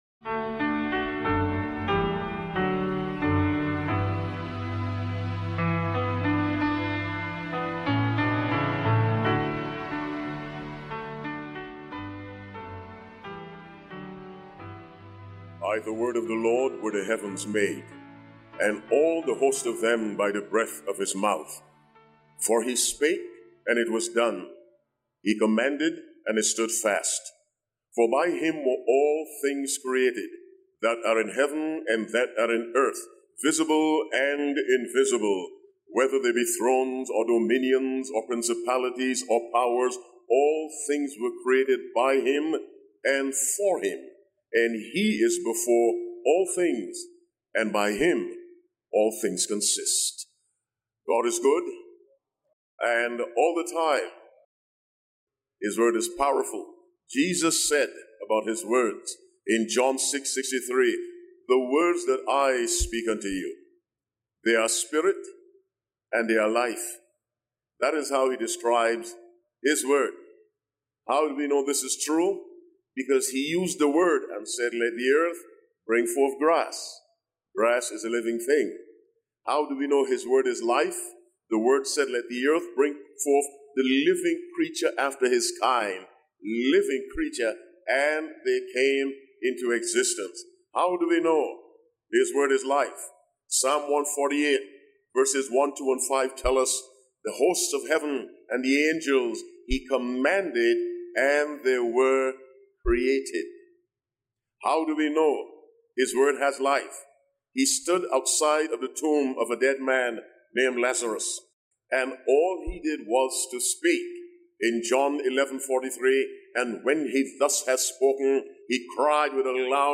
This sermon explores the profound mystery of Christ as the Word made flesh—revealing God’s love, humility, and power through Jesus’ incarnation, suffering, and victory over sin. Emphasizing the life-giving power of God’s Word, it calls believers to rely fully on Scripture, experience true transformation, and live boldly in the mission Christ has entrusted to His followers.